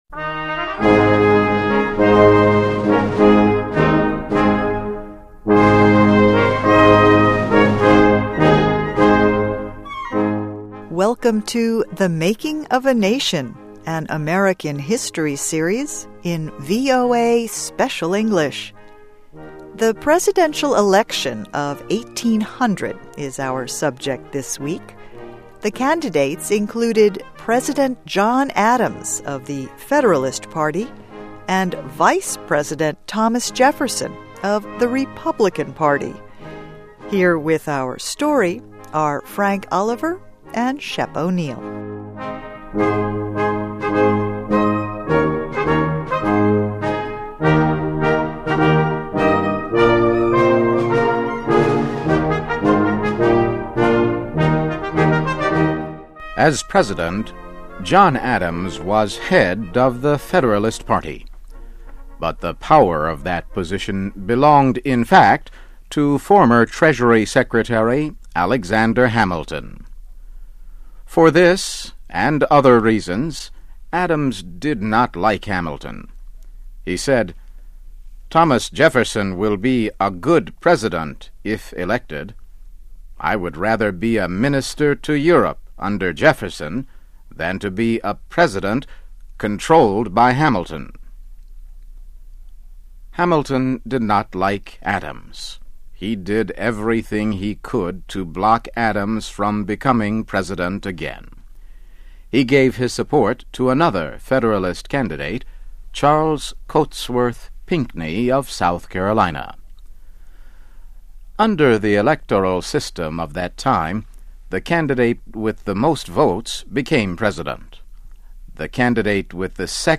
Welcome to THE MAKING OF A NATION – an American history series in VOA Special English.